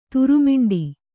♪ turumiṇḍi